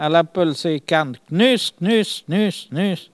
Mémoires et Patrimoines vivants - RaddO est une base de données d'archives iconographiques et sonores.
Elle crie pour appeler les canards